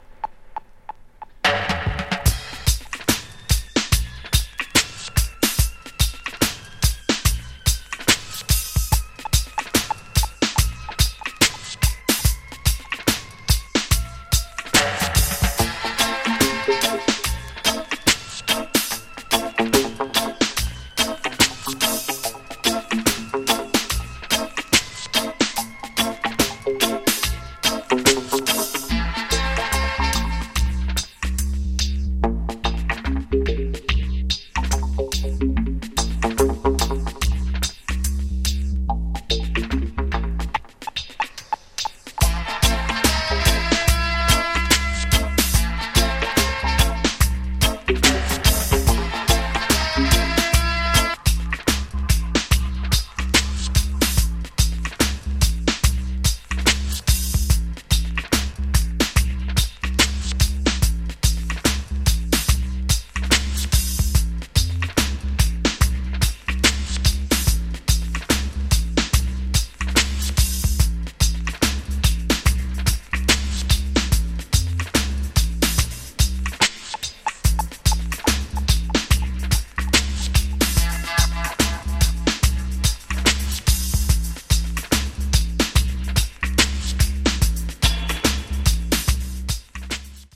DUBPLATE STYLE '90-'99